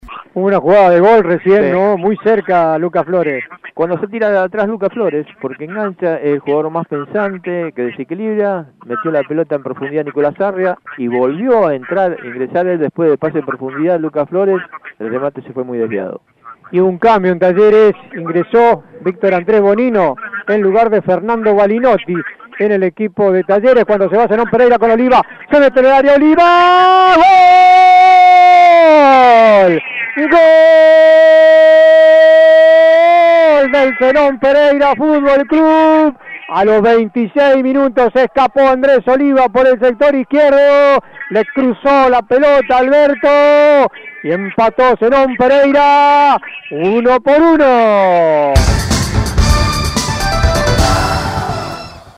Fue transmisión central de la radio
GOLES